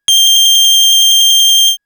Ringing02.wav